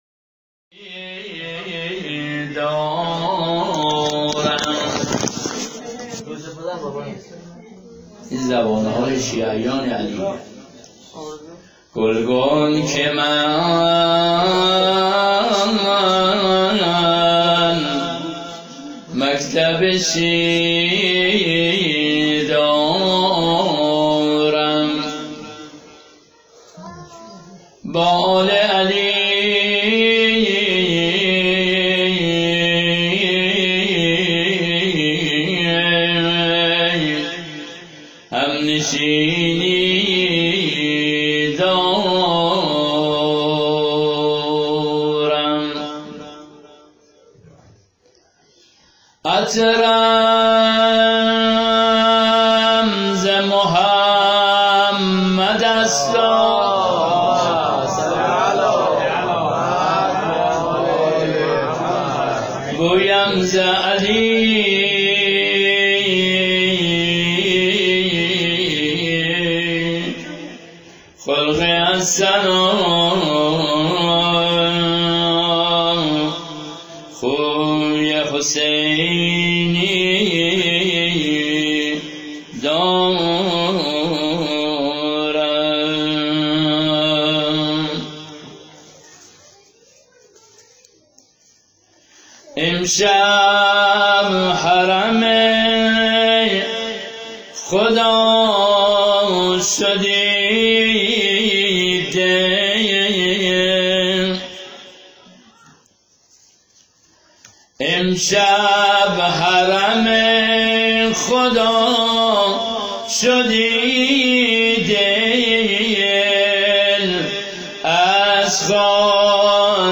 هیأت زوارالزهرا سلام اللّه علیها
مدح